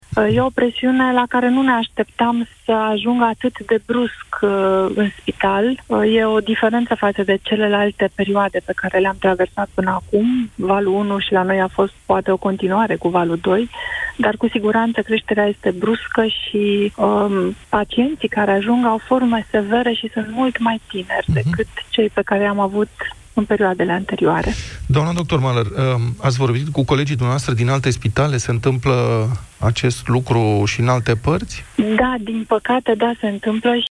în cadrul emisiunii Desteptarea de la Europa FM